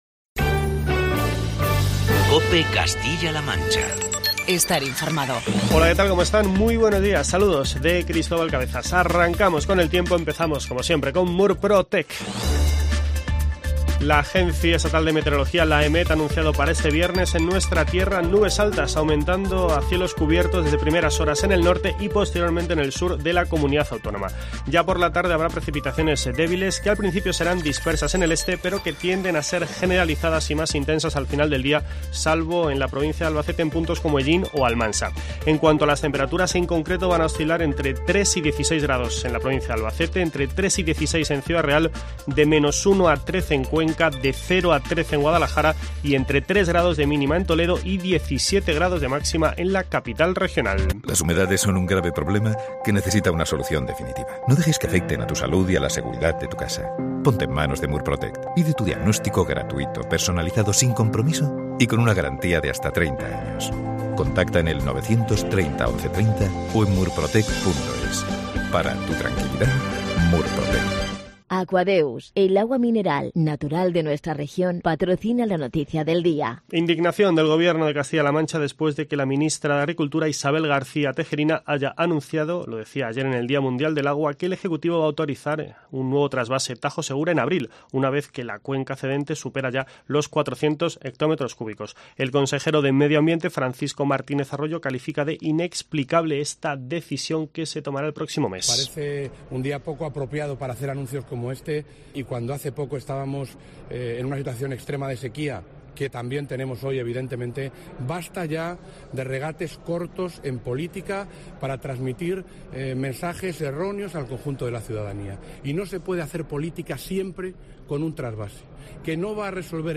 informativos matinales